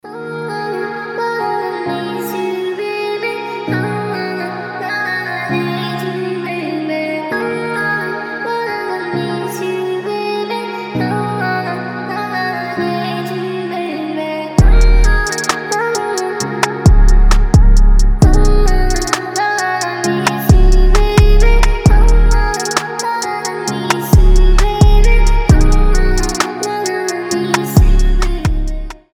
• Качество: 320, Stereo
спокойные
детский голос
Chill
Красивый бит на звонок от любимого или любимой